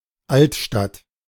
Altstadt (German: [ˈalt.ʃtat]